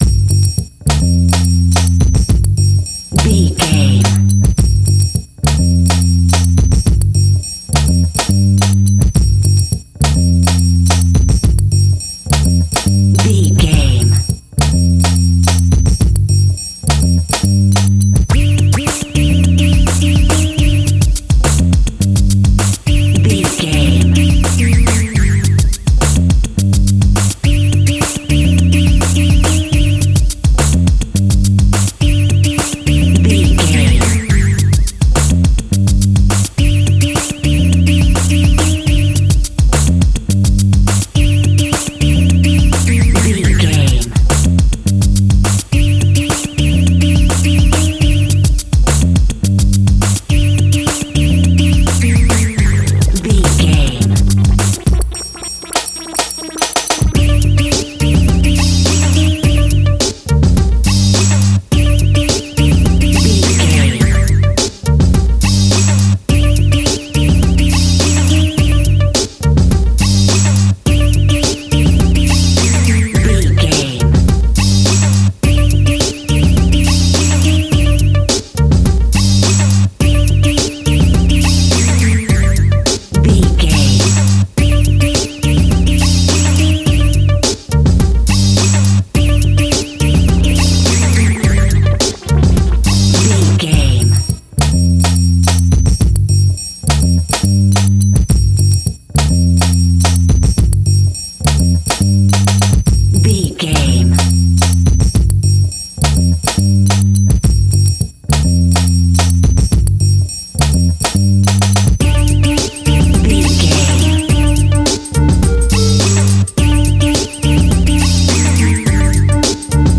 Aeolian/Minor
hip hop instrumentals
downtempo
synth lead
synth bass
synth drums
turntables